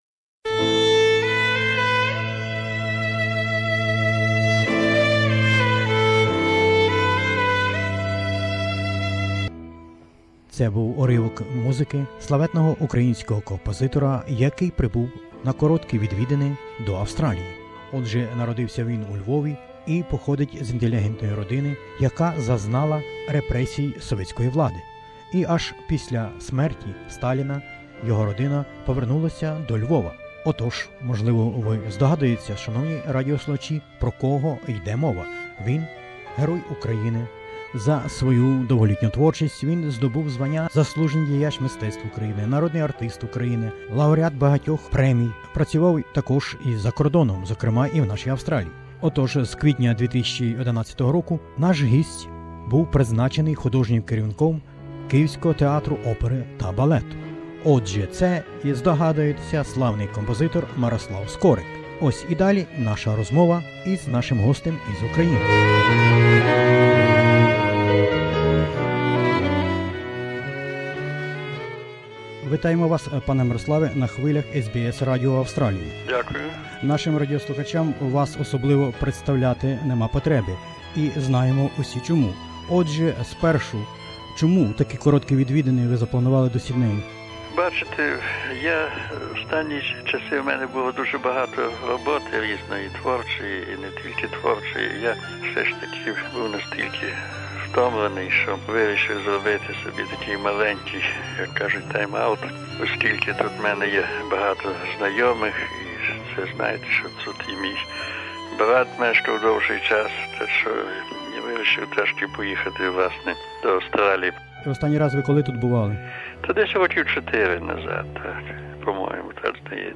Розвмова із Маестром відбулася 5 років тому у час коротких відвідин Сіднею...